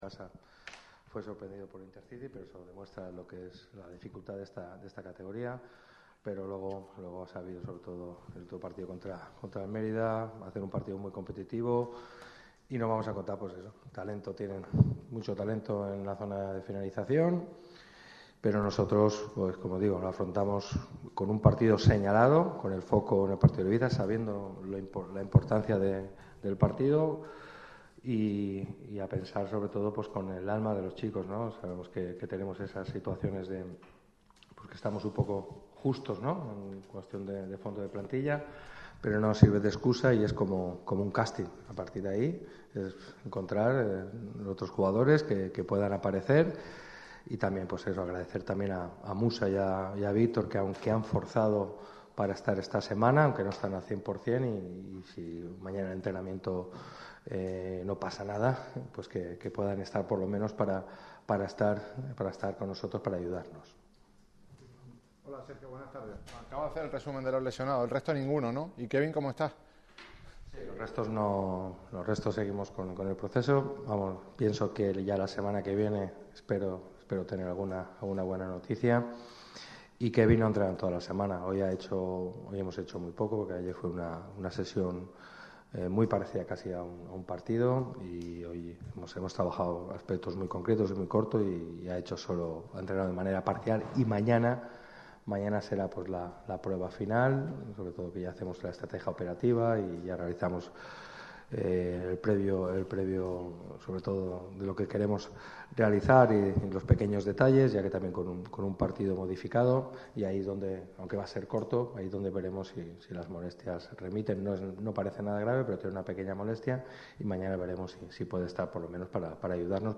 en sala de prensa